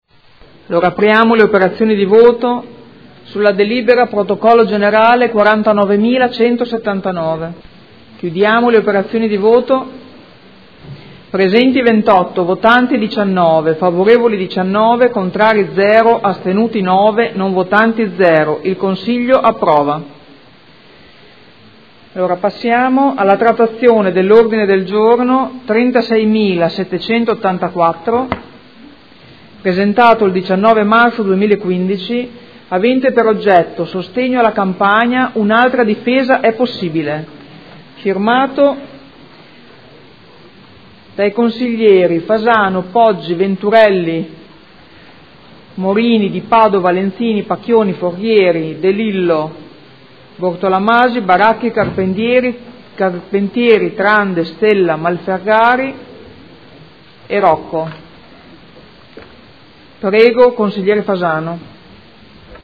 Presidente — Sito Audio Consiglio Comunale
Seduta del 7/05/2015.